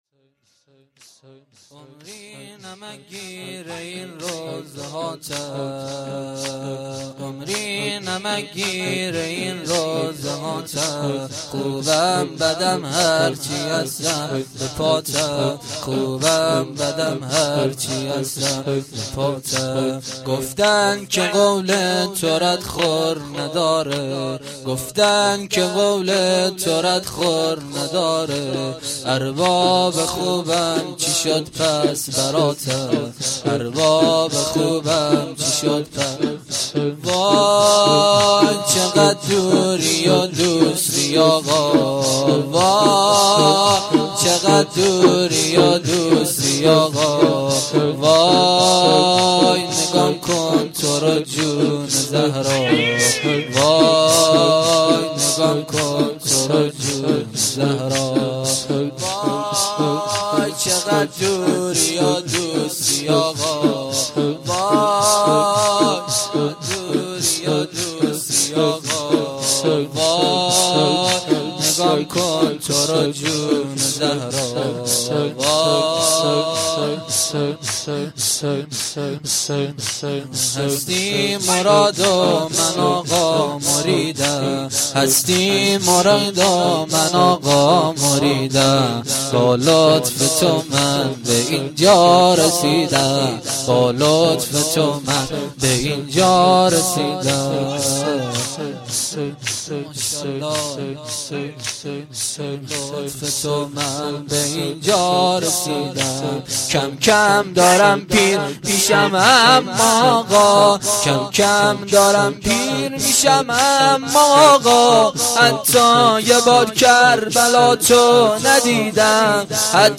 گزارش صوتی جلسه رحلت امام
روضه